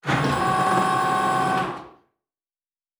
pgs/Assets/Audio/Sci-Fi Sounds/Mechanical/Servo Big 2_1.wav at master
Servo Big 2_1.wav